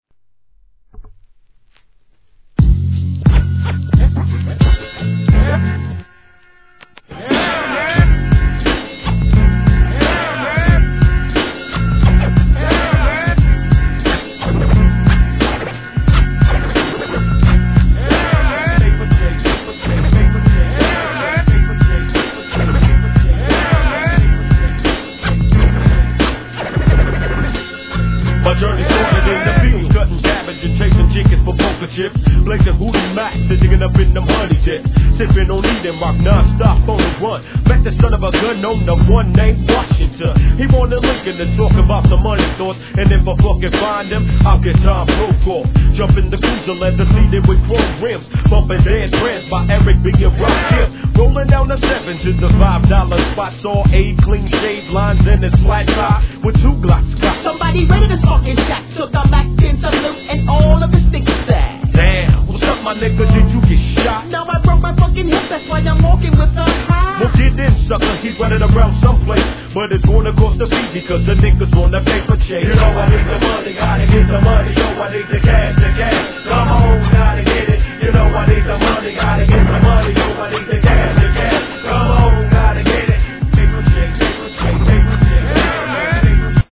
G-RAP/WEST COAST/SOUTH
コーラスも映える最高な仕上がり!!